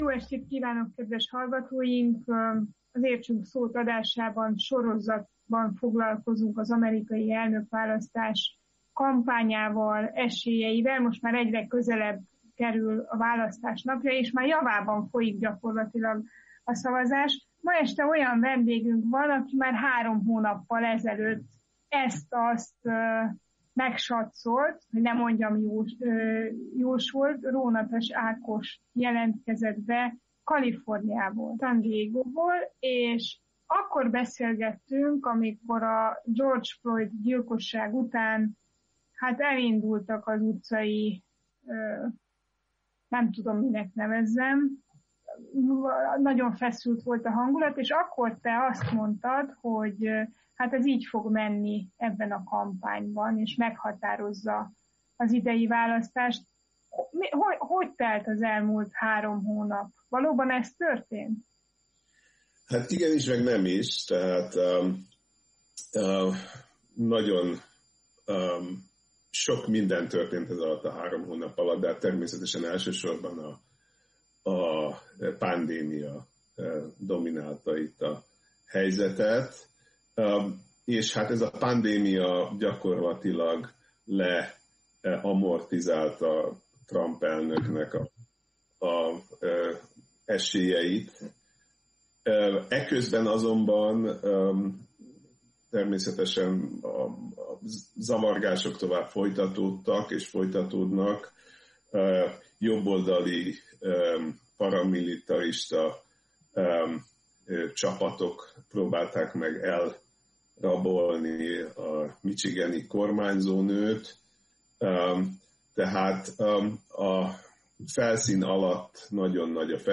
Szociológusként arról is kérdeztük, miért az utcai mozgalmak bizonyultak meghatározónak az idei választási kampányban, és valóban igaz-e, hogy a rendkívül aktív új szavazók, a legfiatalabb nemzedék döntheti el, ki lesz az Egyesült Államok új elnöke. Amerikai választási beszélgetéssorozatunk első része itt hallgatható vissza: